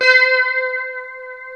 C#5 HSTRT MF.wav